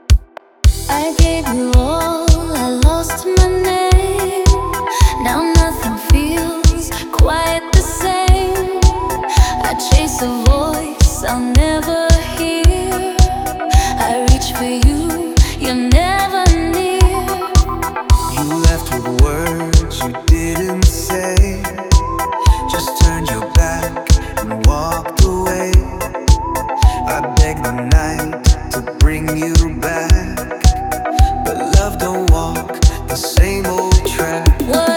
2025-04-29 Жанр: Танцевальные Длительность